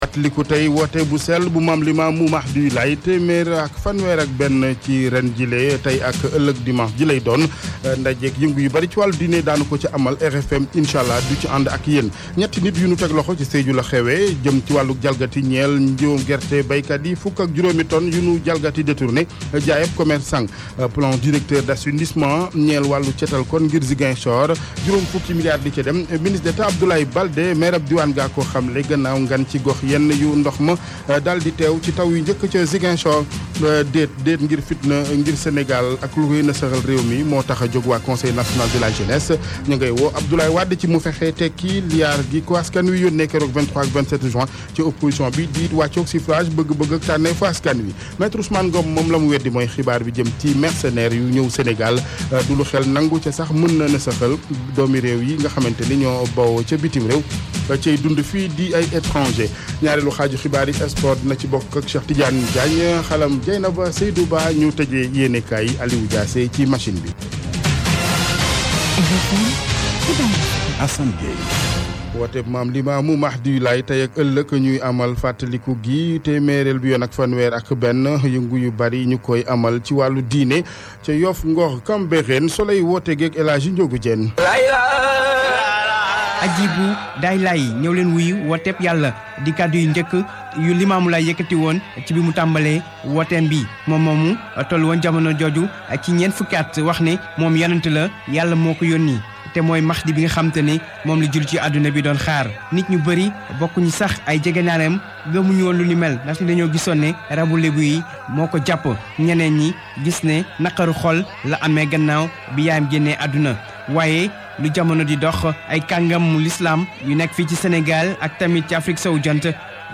[ AUDIO ] Le Journal wolof de la RFM du 02 juillet